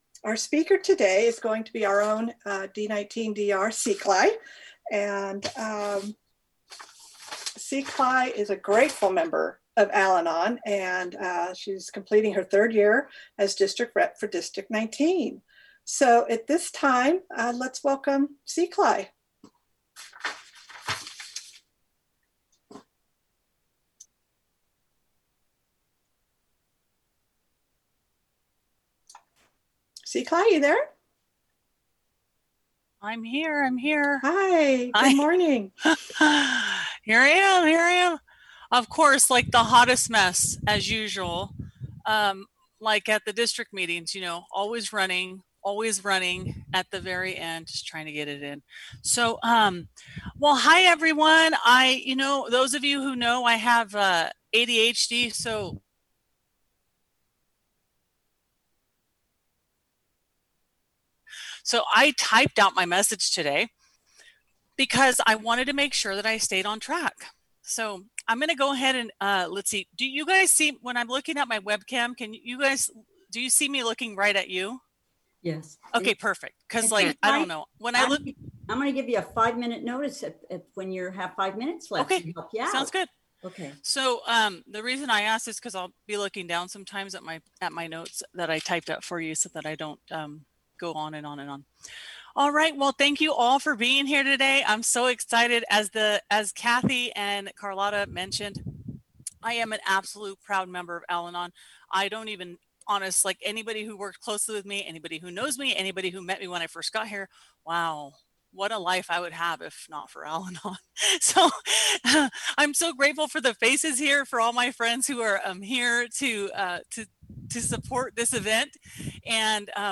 NCWSA District 19 - Monthly Special Event FUNraiser Zoom Workshop